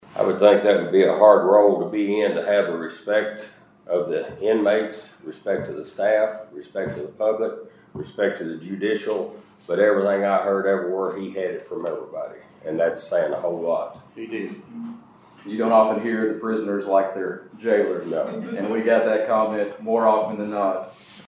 The Caldwell County Fiscal Court paused its Tuesday session to honor the late Jailer Willie Harper, who was remembered fondly for his dedication, financial stewardship, and ever-present smile following his courageous battle with cancer.